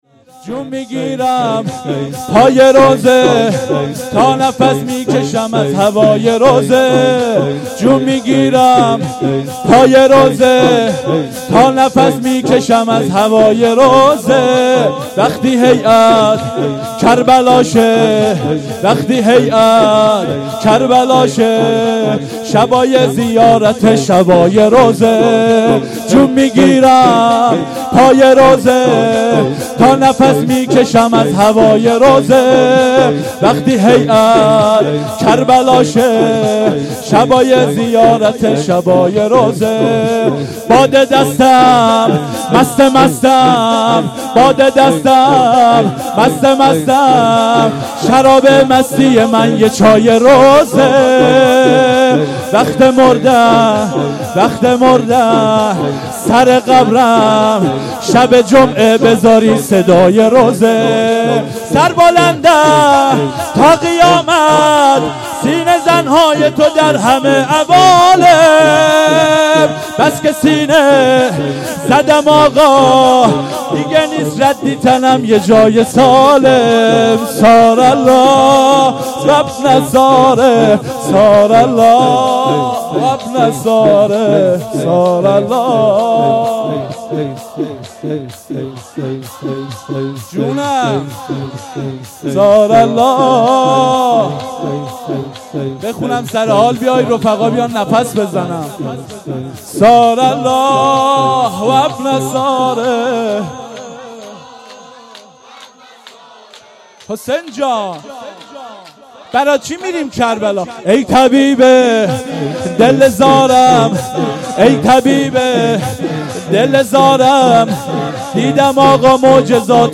شور1